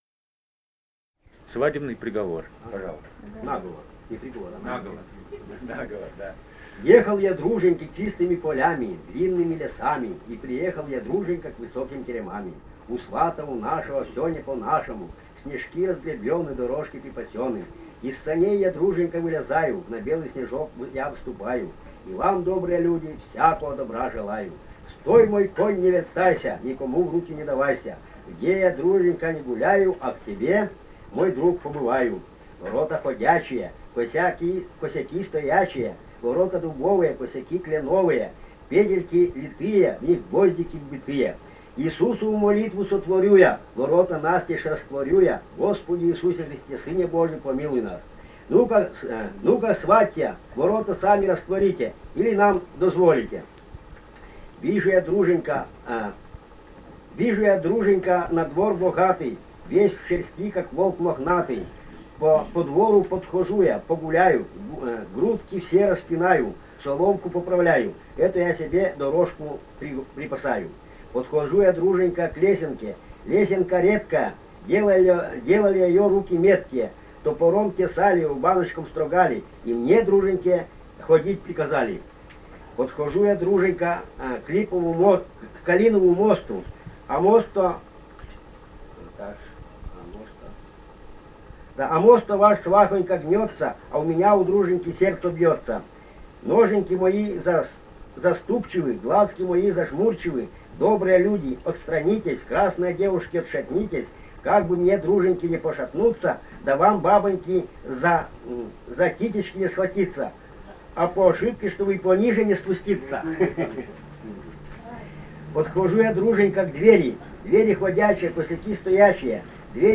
«Ехал я, друженька» («на́говор» дружки на свадьбе).
Костромская область, пос. Кадый Кадыйского района, 1964 г. И0791-07